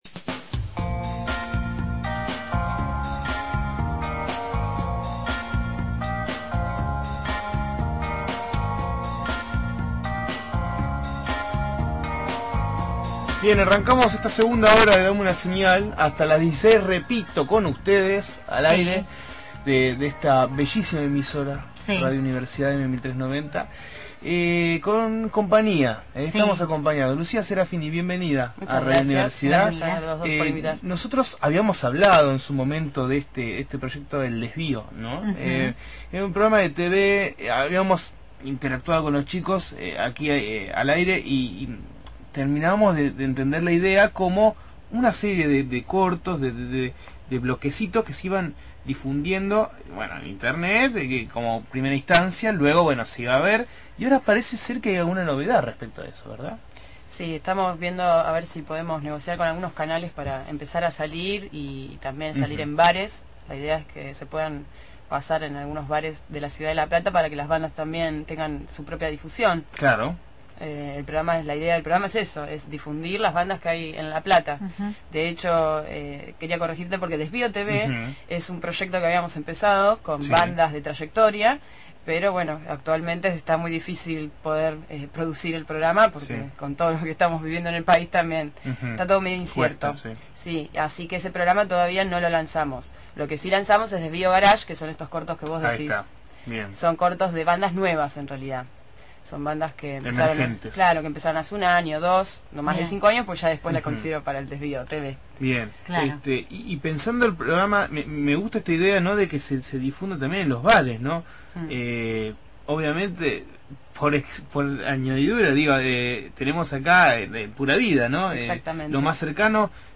fue entrevistada en Dame Una Señal sobre el programa Desvío TV.